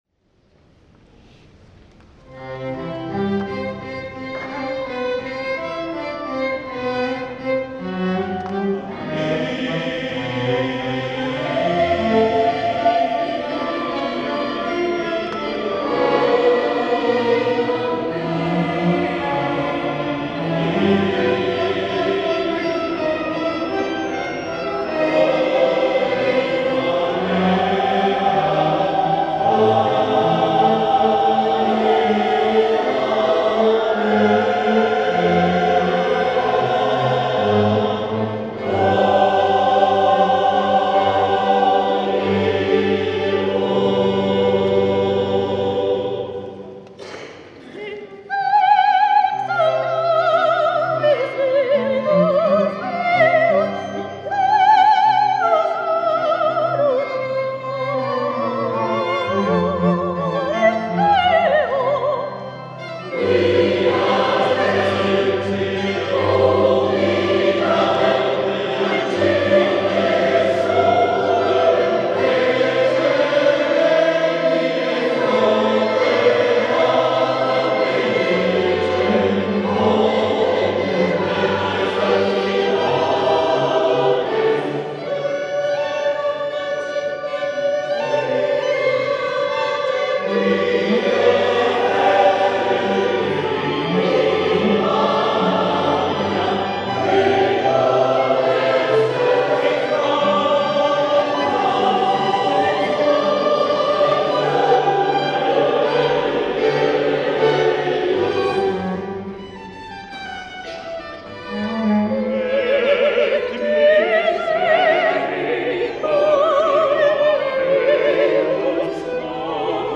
S. Gaudenzio church choir Gambolo' (PV) Italy
18 Dicembre 2017  dal "Concerto di Natale" MAGNIFICAT